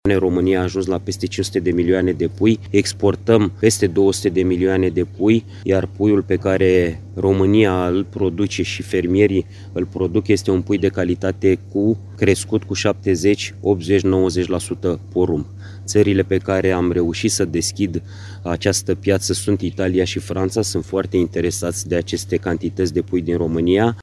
Sprijinul statului pentru proiectele din zootehnie va fi de 65%, a anunțat ministrul Florin Barbu, prezent în județul Hunedoara.
Ministrul Agriculturii, Florin Barbu, a anunțat astăzi, la vizita în județul Hunedoara, că programul va începe în circa două luni de zile și vor fi eligibile fermele cu 300 de capete de bovine.